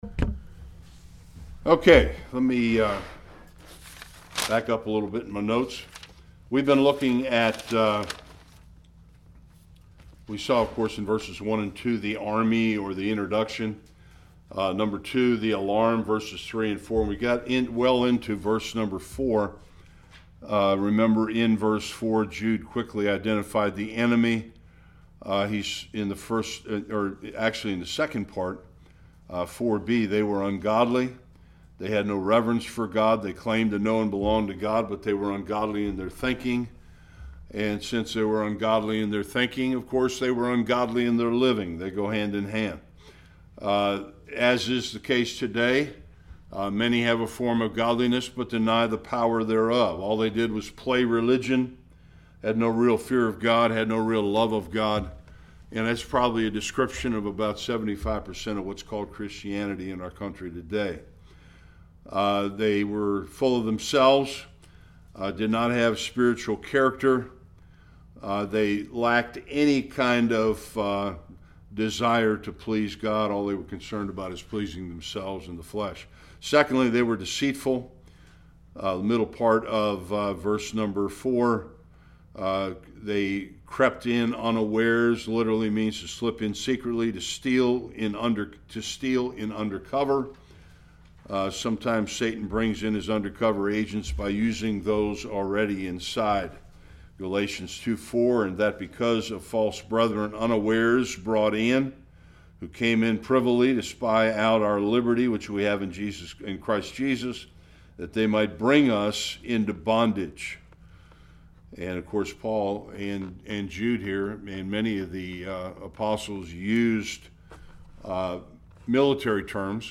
Jude 4 Service Type: Bible Study Jude exposed those who were denying God’s truth.